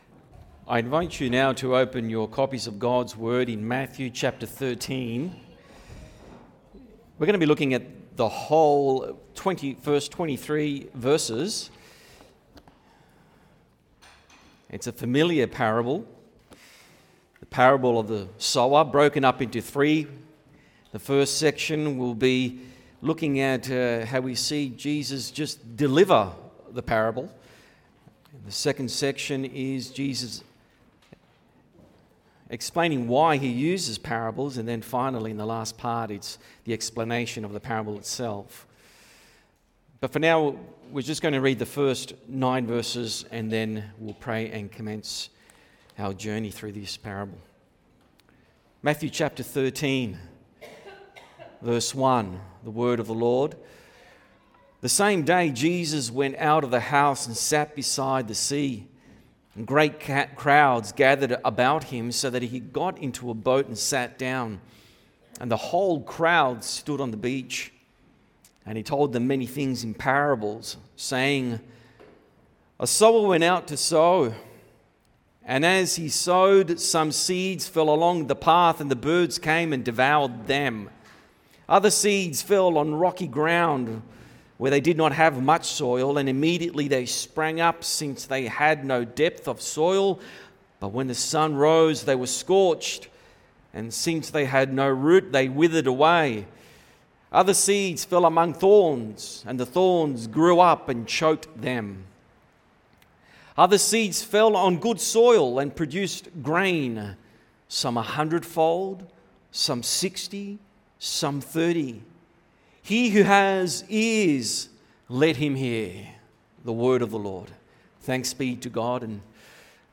SERMONS 2025
Every Sunday at Providence Reformed Baptist Church – 9:30am